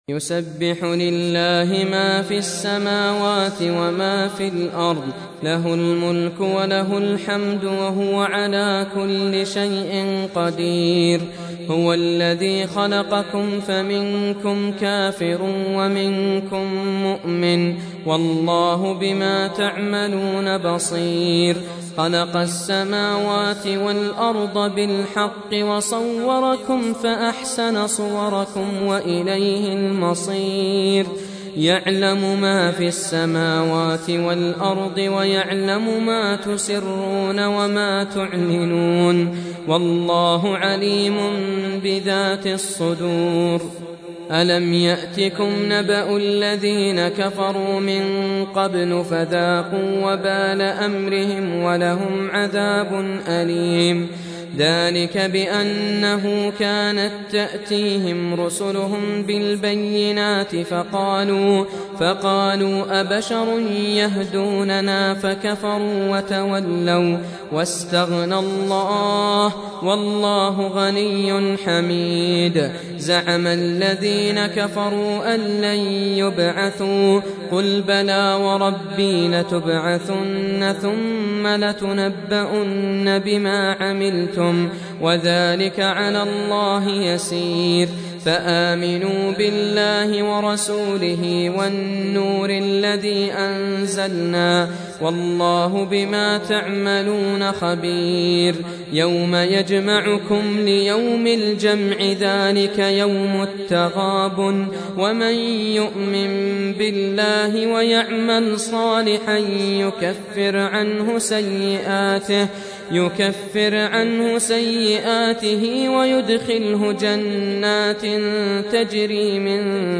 Surah Repeating تكرار السورة Download Surah حمّل السورة Reciting Murattalah Audio for 64. Surah At-Tagh�bun سورة التغابن N.B *Surah Includes Al-Basmalah Reciters Sequents تتابع التلاوات Reciters Repeats تكرار التلاوات